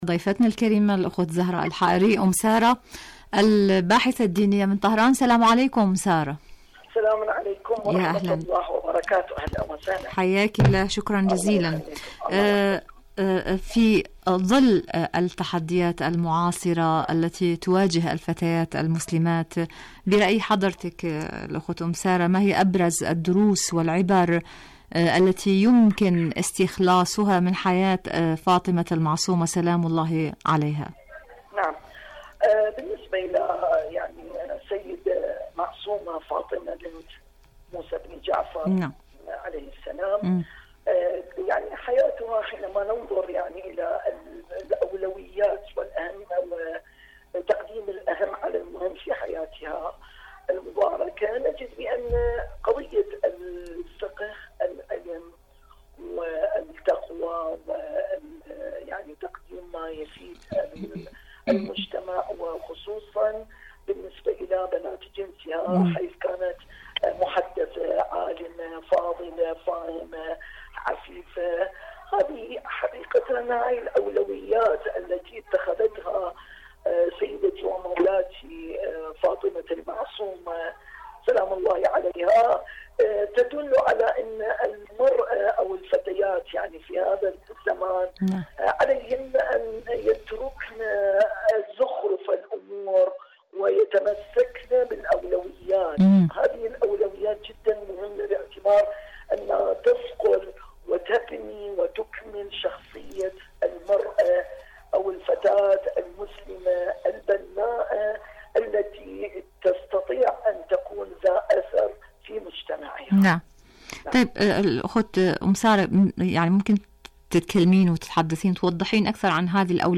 مقابلات إذاعية الفتاة النموذجية مواصفات وأدوار